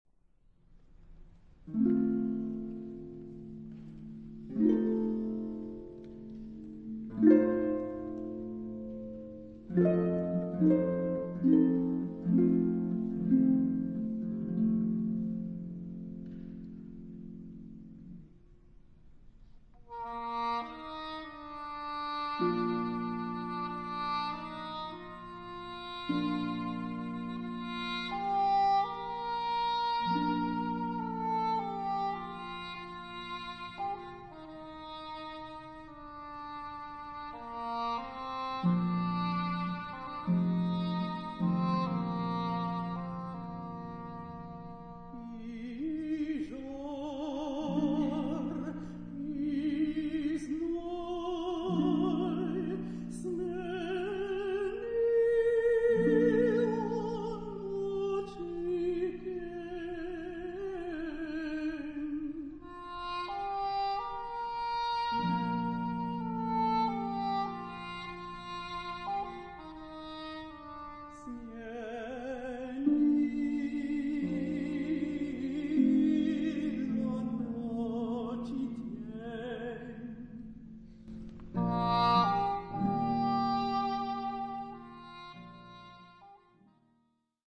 Arranged for contralto, English horn, & harp